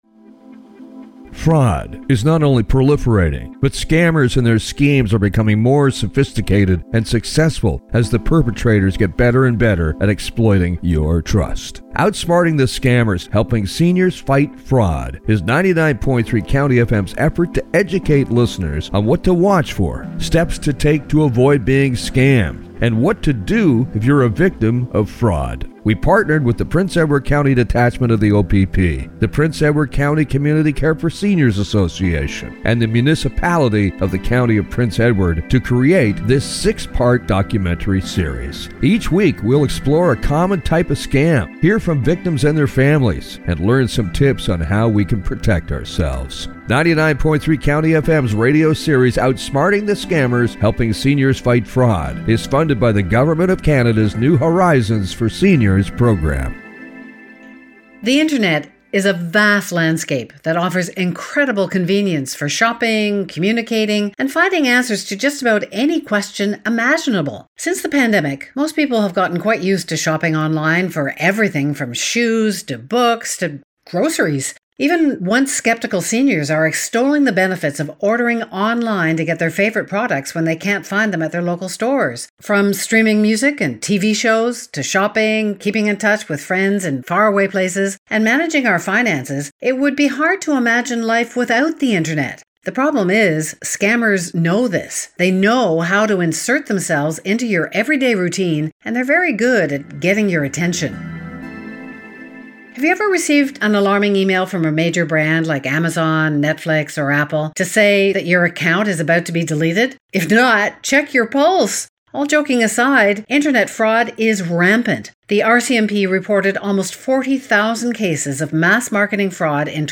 Original 6 Part Multi-Part Radio Documentary